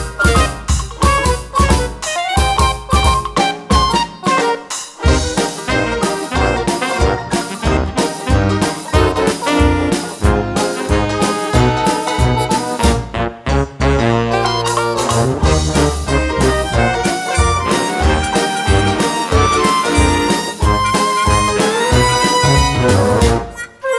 Duet Version